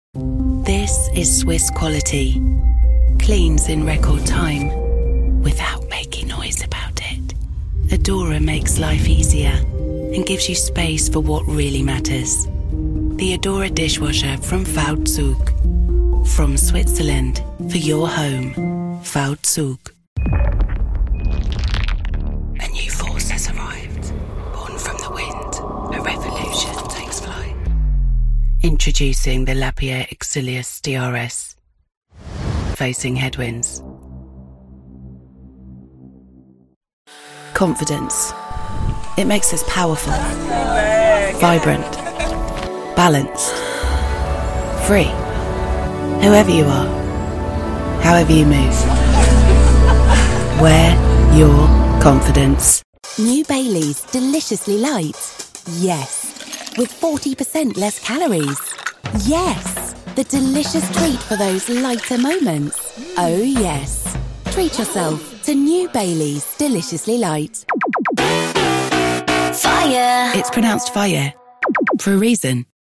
Inglés (Reino Unido)
Warm Conversational Cool